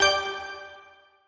Appear_Window_Sound.mp3